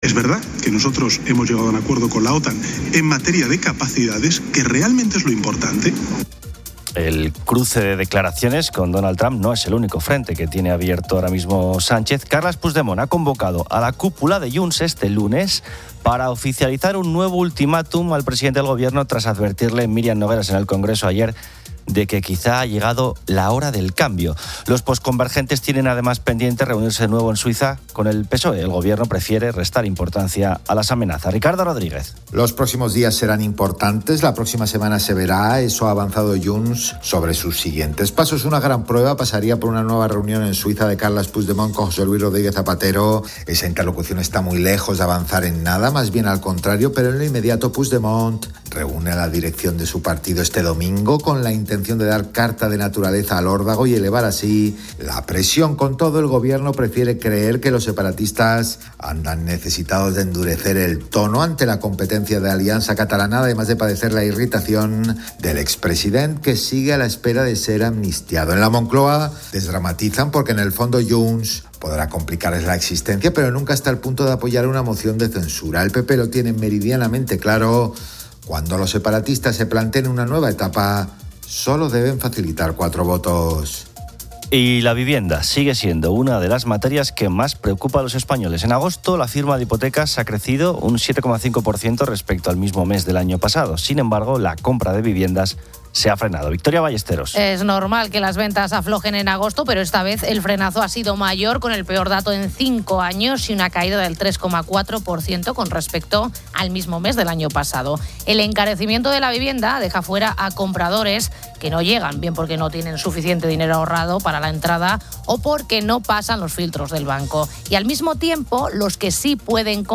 ''El audio comienza con una sección de noticias.